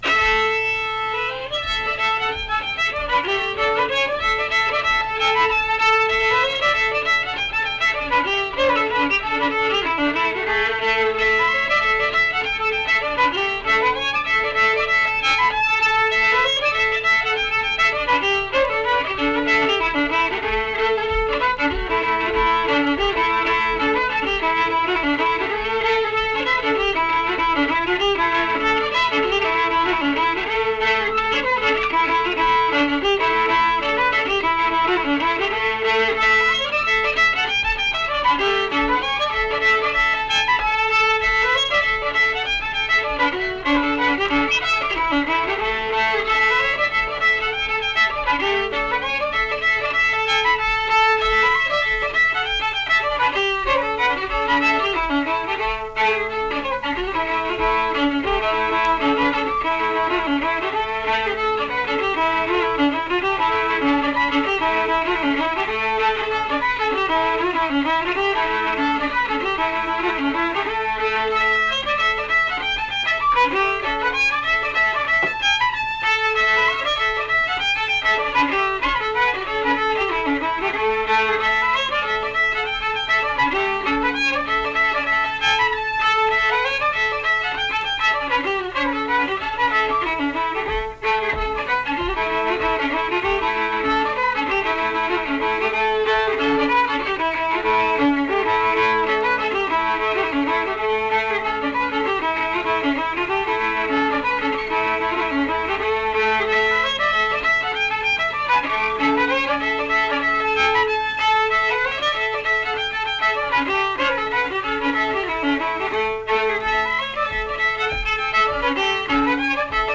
Unaccompanied fiddle music
Verse-refrain 5(2).
Instrumental Music
Fiddle